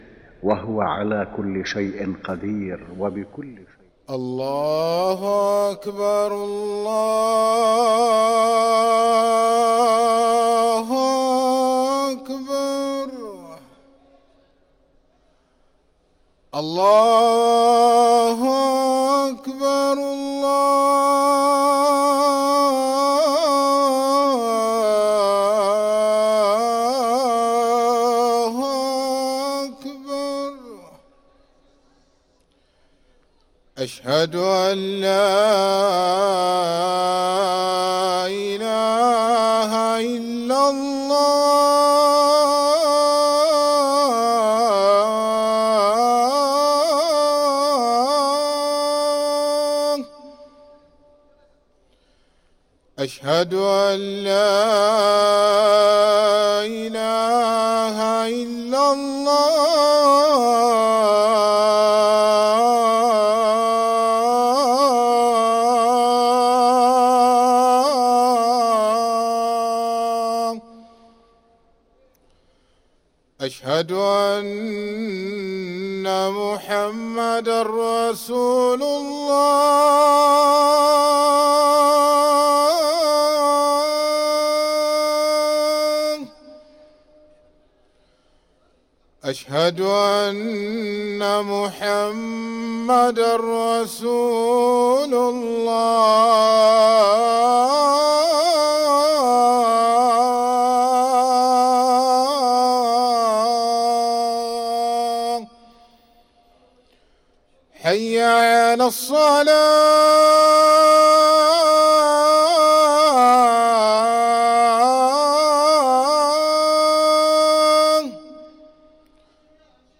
أذان الظهر للمؤذن سعيد فلاته الأحد 22 ذو القعدة 1444هـ > ١٤٤٤ 🕋 > ركن الأذان 🕋 > المزيد - تلاوات الحرمين